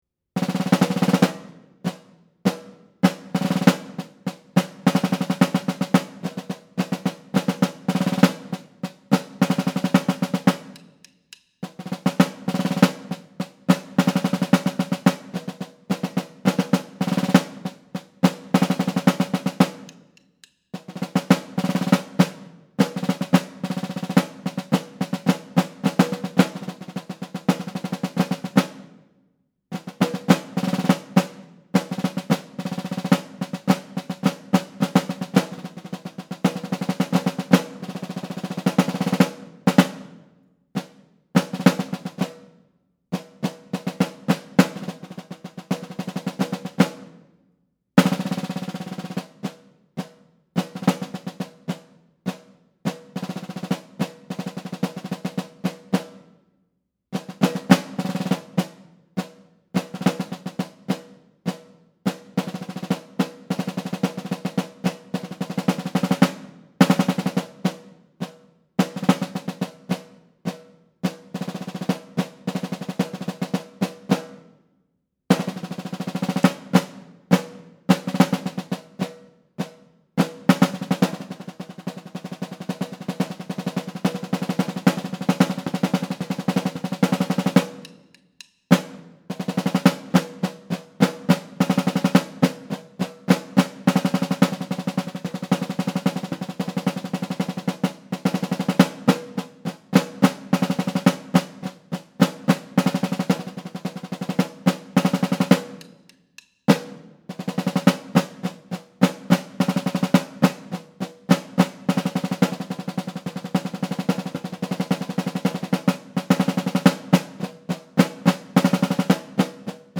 Voicing: Snare Drum Collection